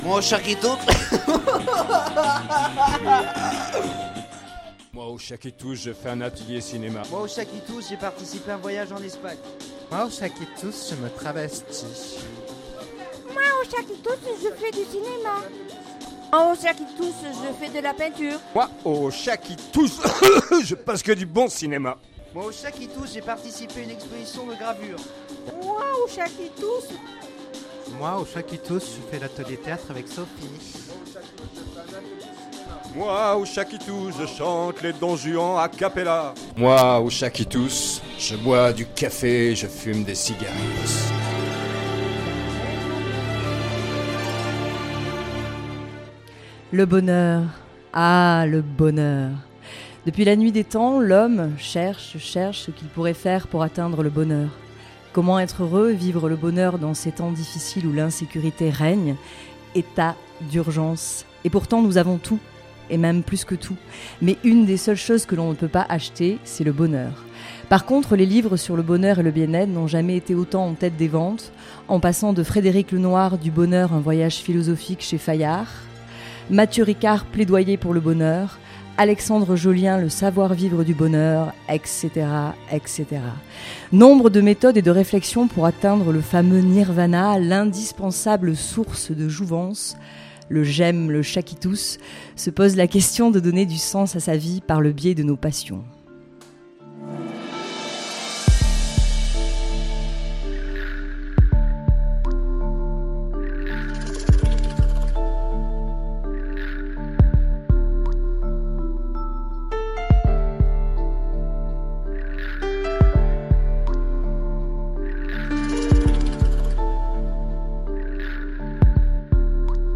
Après plusieurs mois de travail et de répétitions, ils nous dévoilent cette belle émission, aujourd'hui, en direct, sur l'antenne de Fréquence mistral.